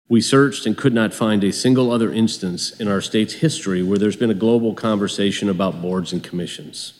Iowa Department of Management director Kraig Paulsen, who led the group, says it’s an important conversation.